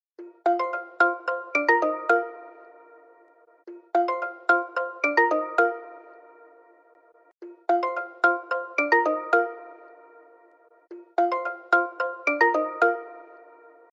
Входящий вызов